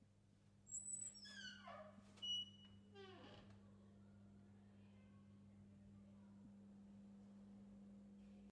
环境电子噪音
描述：用发电机制造的声音，但也可用于氛围。
标签： 环境 发电机 雄蜂 嗡嗡声
声道立体声